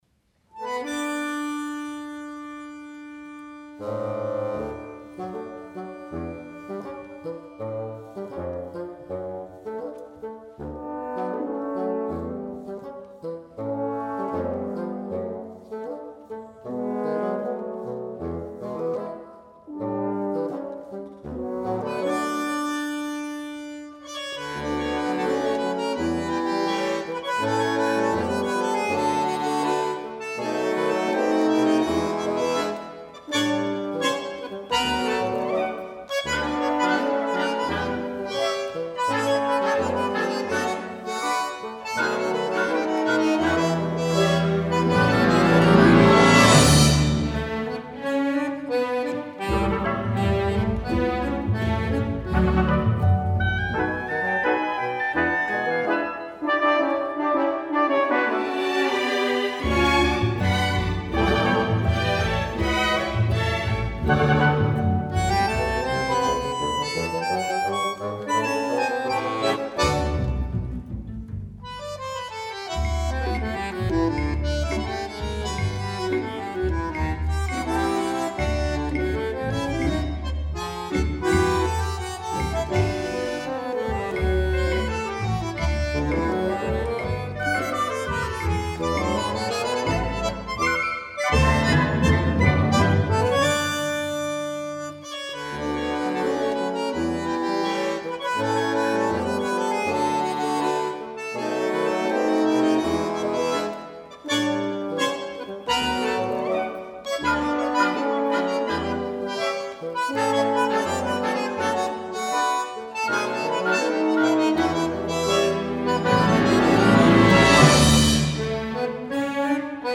bandoneón und sinfonieorchester